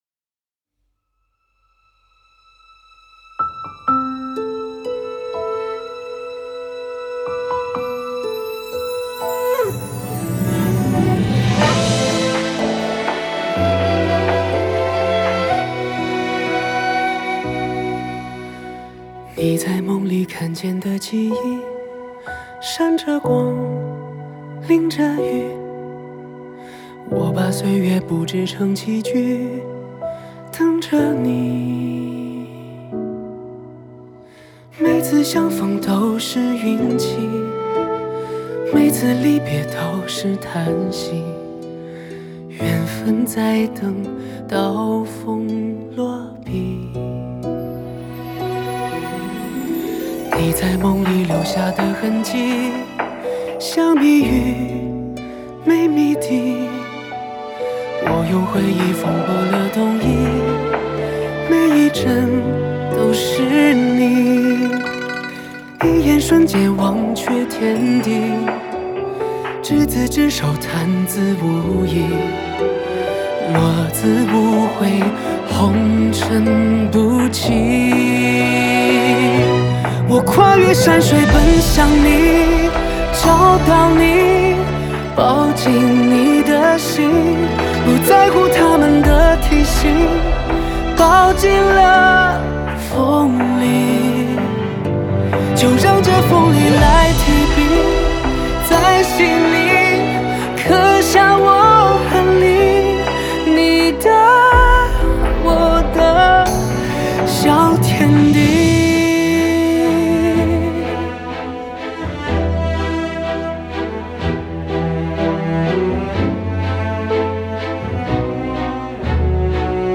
Ps：在线试听为压缩音质节选，体验无损音质请下载完整版
弦乐